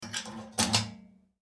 safe_close.ogg